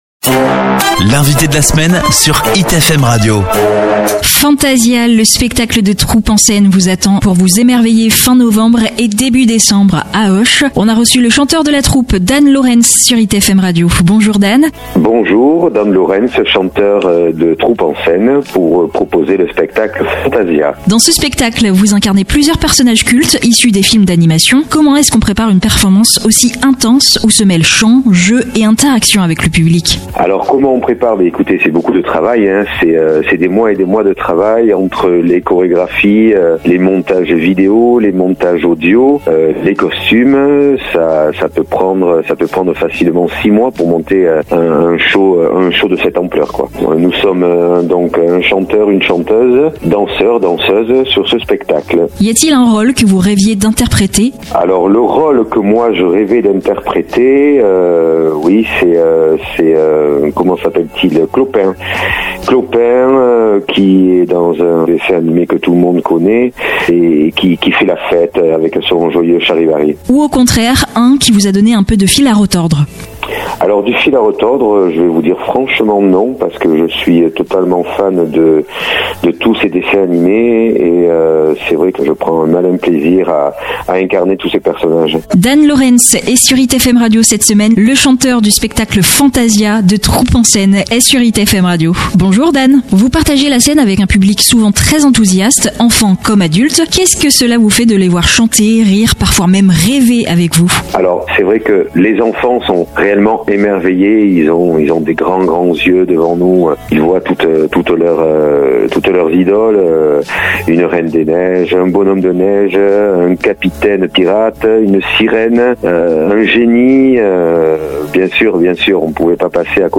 L'Invité de la Semaine